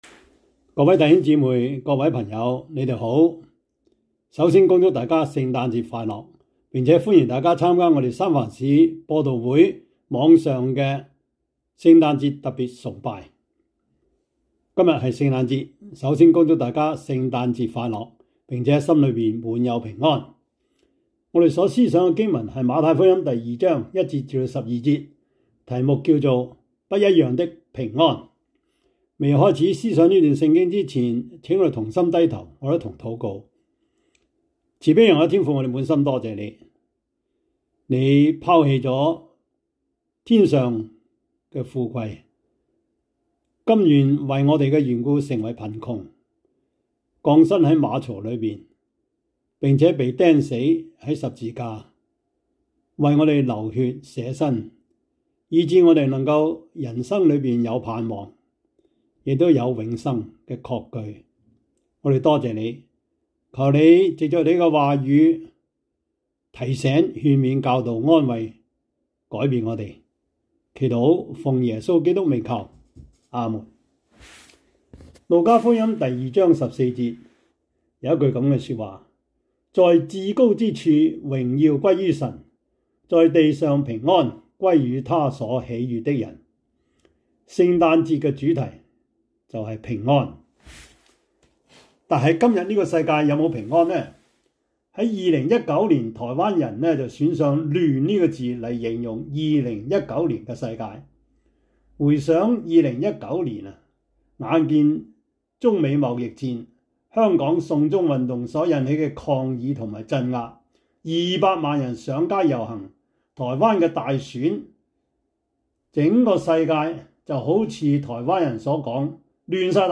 馬太福音 2:1-12 Service Type: 主日崇拜 馬太福音 2:1-12 Chinese Union Version